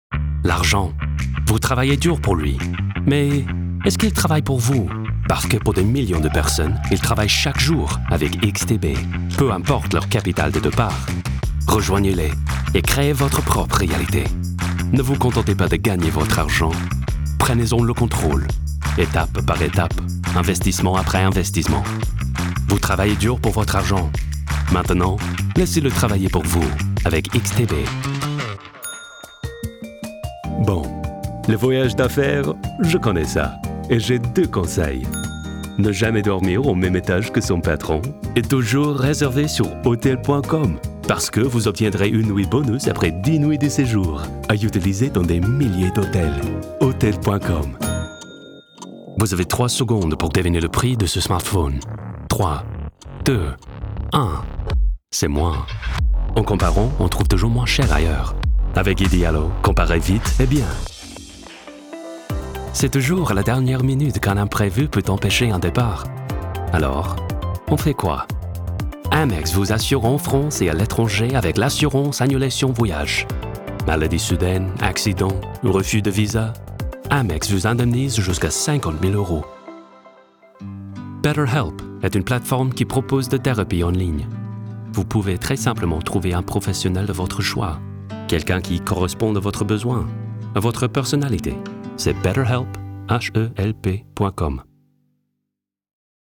VO_Français_2025